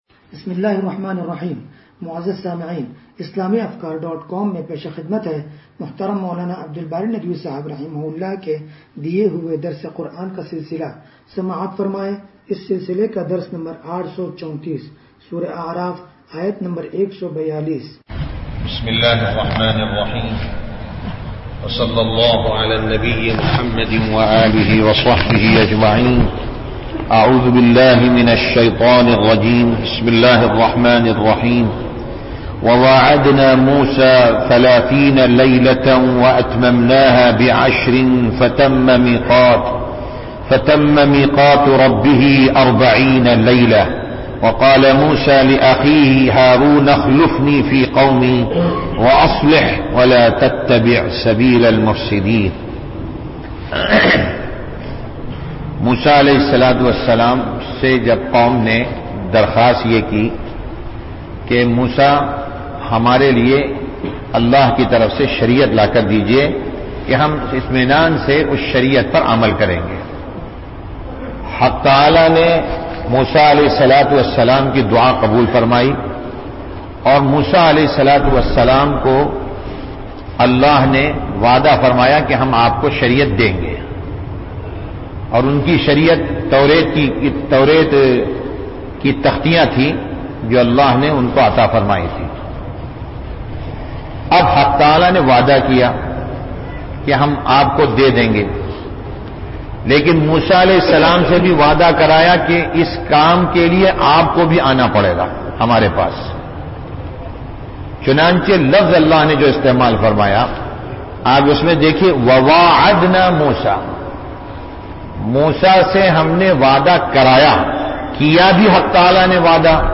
درس قرآن نمبر 0834
درس-قرآن-نمبر-0834.mp3